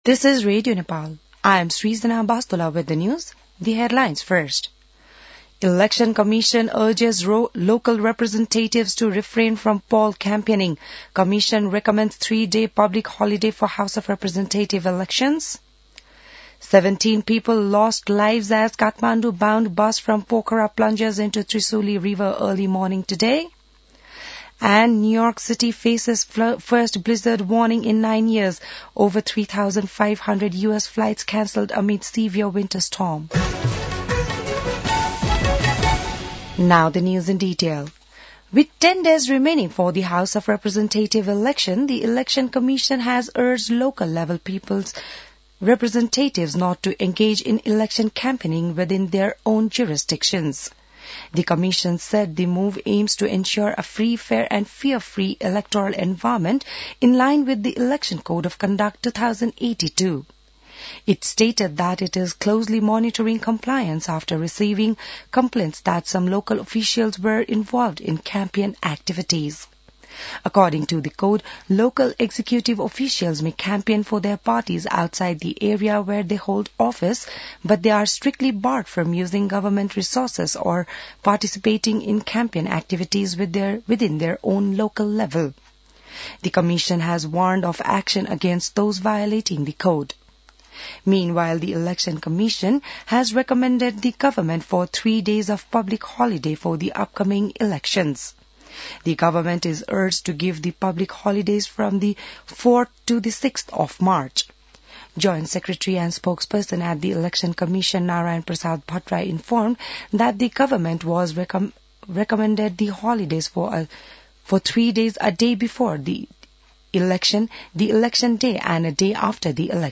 An online outlet of Nepal's national radio broadcaster
बिहान ८ बजेको अङ्ग्रेजी समाचार : ११ फागुन , २०८२